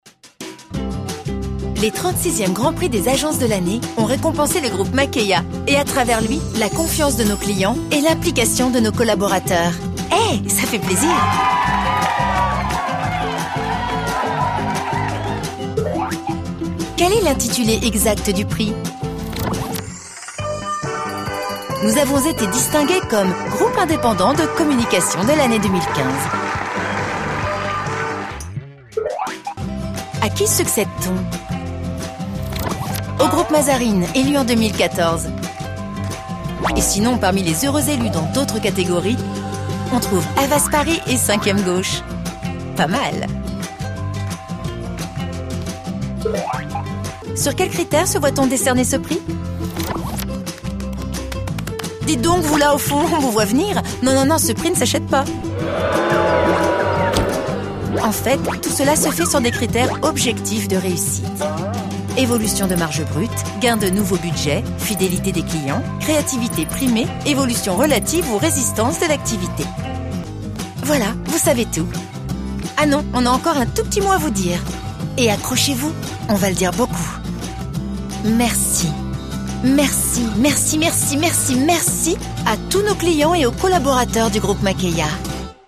légère // tonique
Agence-Makeihia-Légère-tonique.mp3